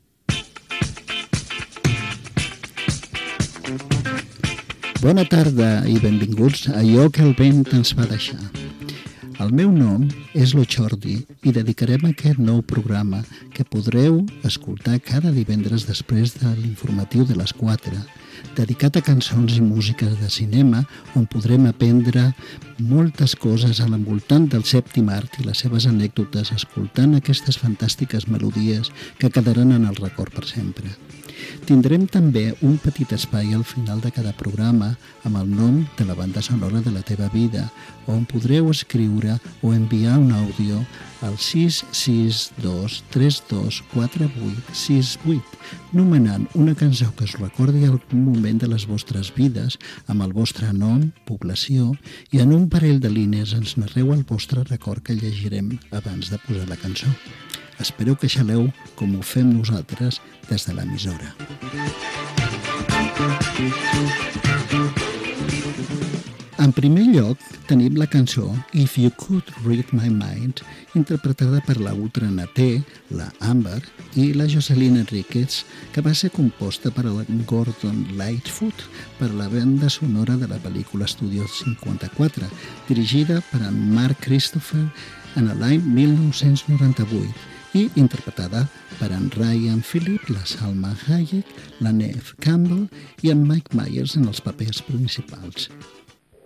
Inici del primer programa de la sèrie dedicat a cançons i música de cinema. Presentació, contingut que s'oferirà amb el telèfon de contacte par participar-hi i tema musical
Musical